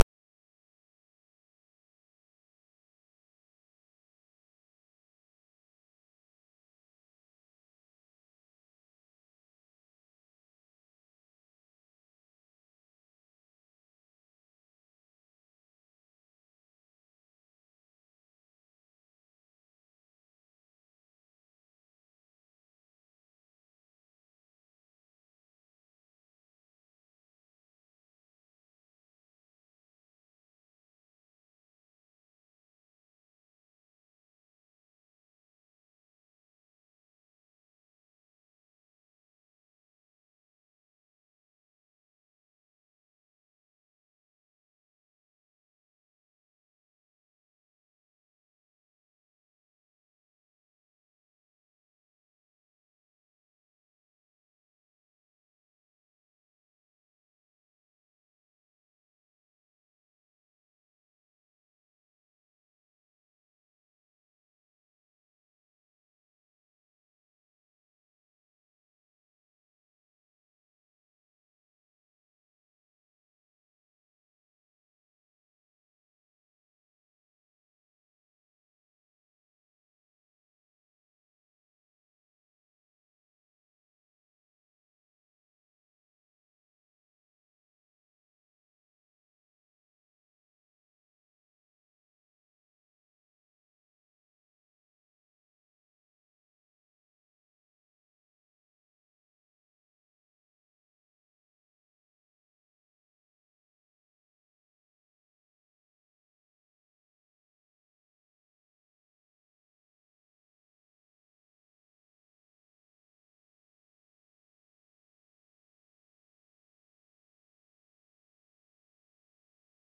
Commodore SID Music File